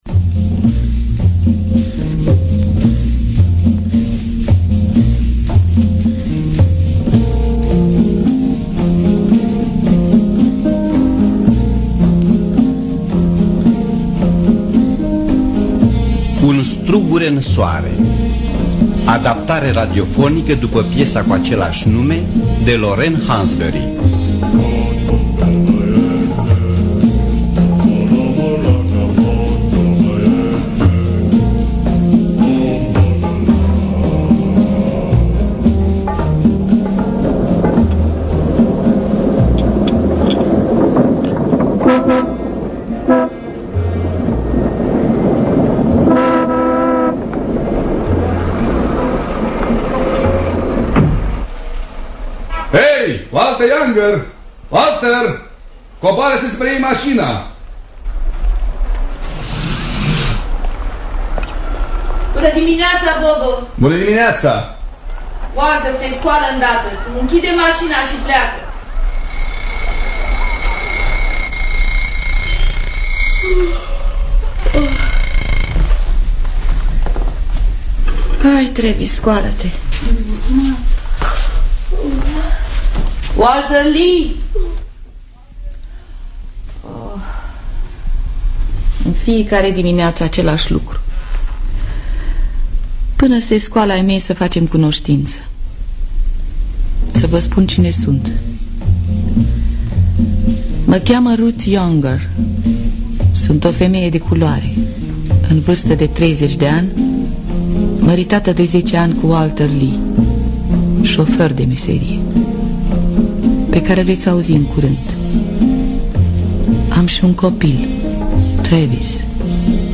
Un strugure în soare de Lorraine Vivian Hansberry – Teatru Radiofonic Online
Înregistrare din anul 1960 (27 noiembrie).